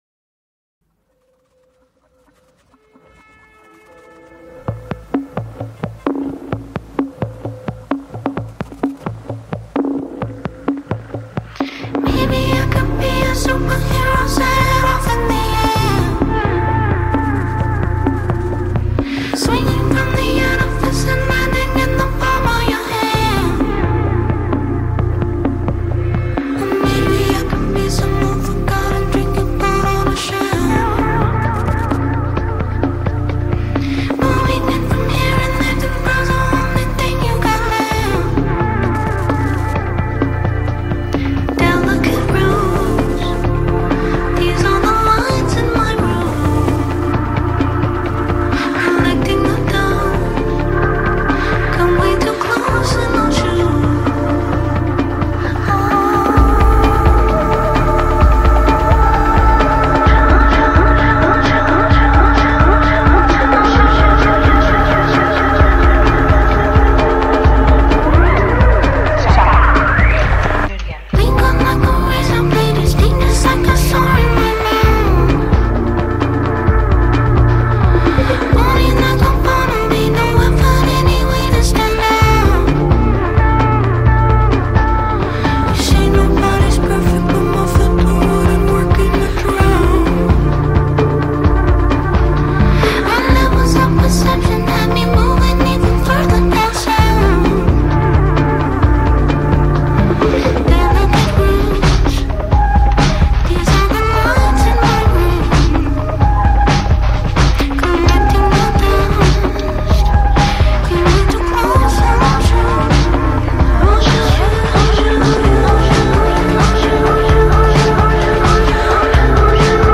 Award winning singer-songwriter